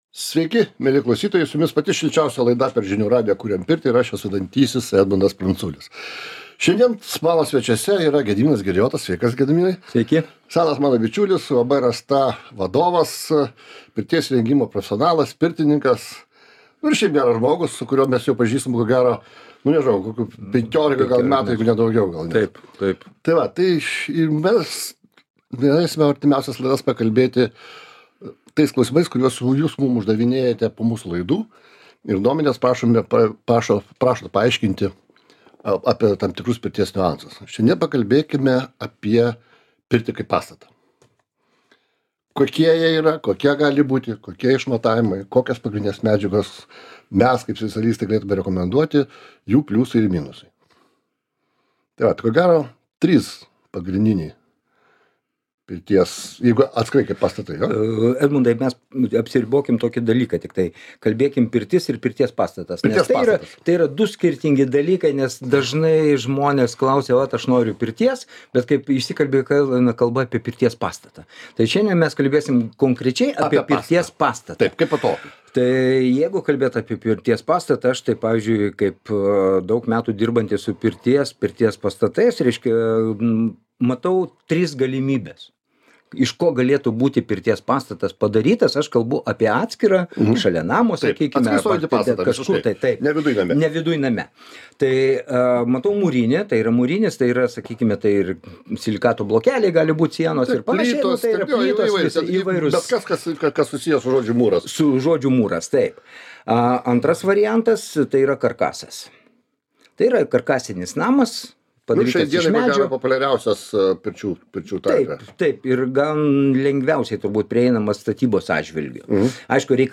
Pašnekovas